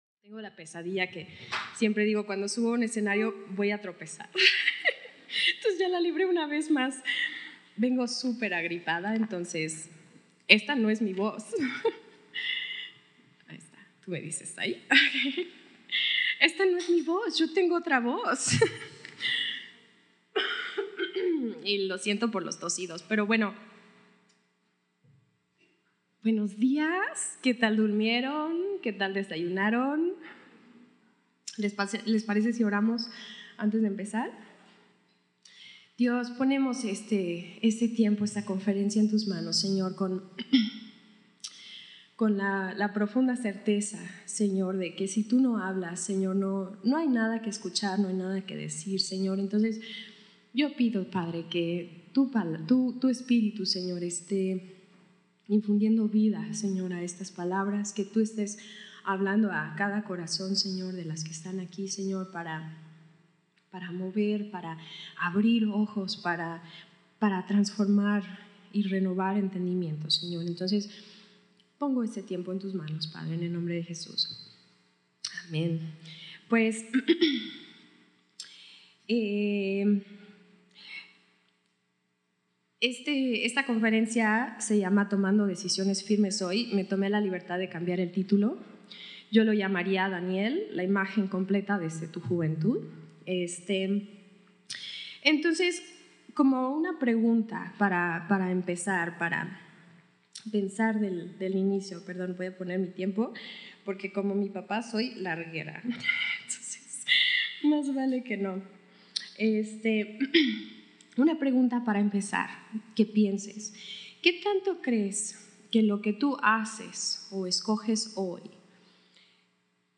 La Imágen Completa | Retiro de Jovencitas